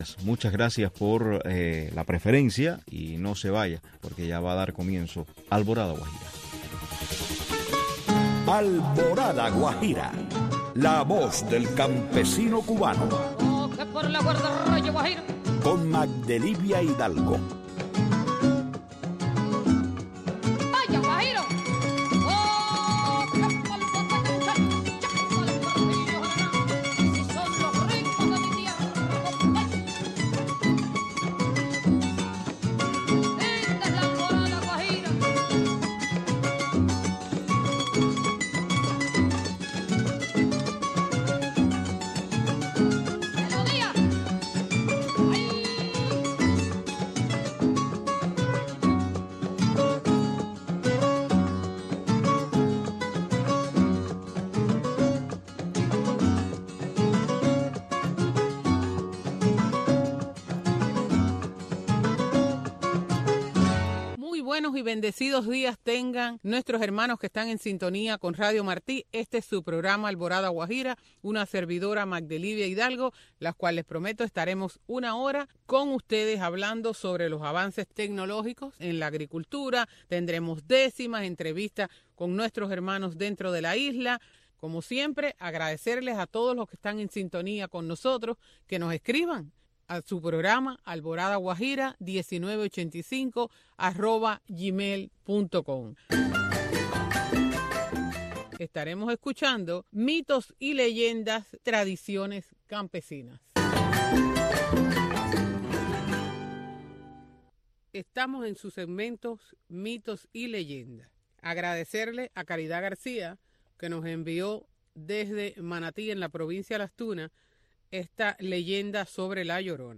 Una hora con temas para el campesino, entrevistas y música.